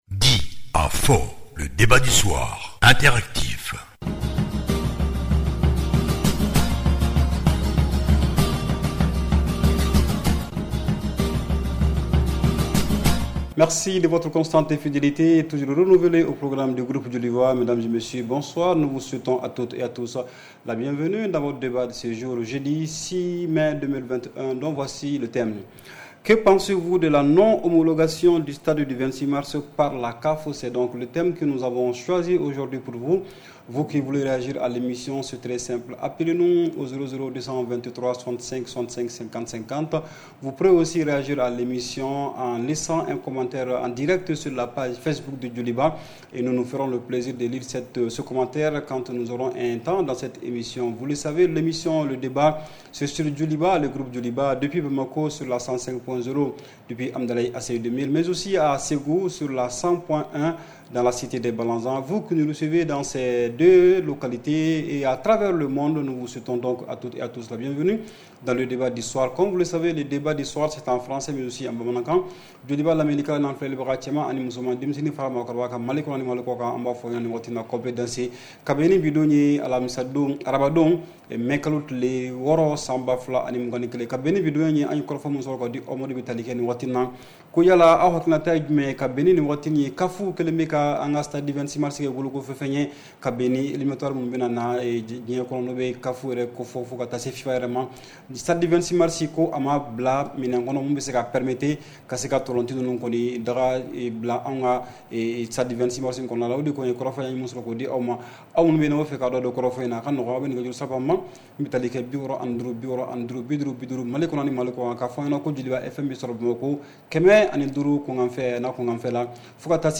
REPLAY 06/05 – « DIS ! » Le Débat Interactif du Soir